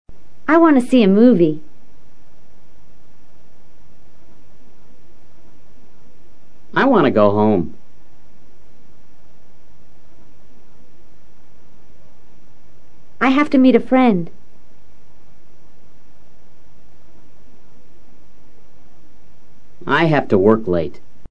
Pronunciación reducida de WANT TO y HAVE TO
En las conversaciones informales los verbos WANT TO y HAVE TO suelen pronunciarse en forma más abreviada.